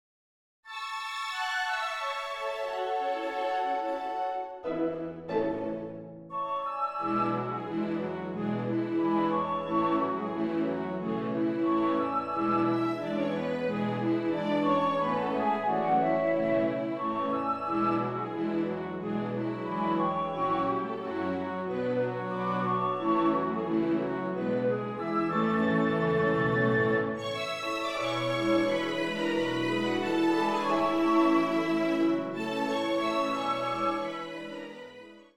Duett
Orchester-Sound